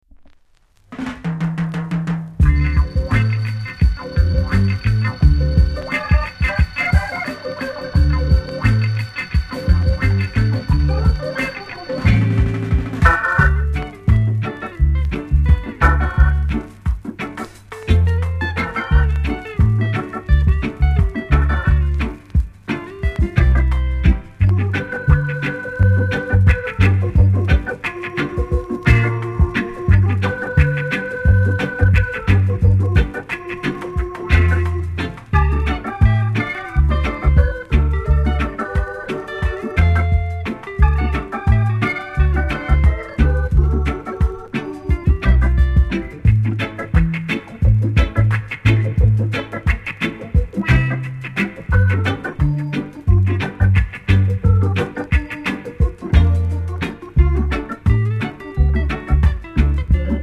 ※小さなチリノイズが少しあります。
コメント FUNKY INST!!